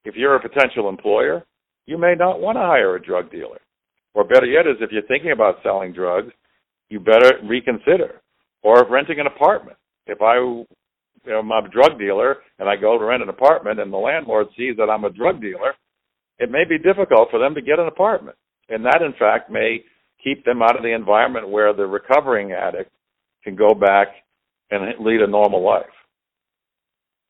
NEW HAMPSHIRE REPRESENTATIVE JACK FLANAGAN SAYS A PROPOSED REGISTRY OF HEROIN DEALERS COULD ACT AS A DETERRENT.
CUT-NH-REP-JACK-FLANAGAN-SAYS-A-REGISTRY-OF-HEROIN-DEALERS-COULD-ASK-AS-A-DETERRENT-.mp3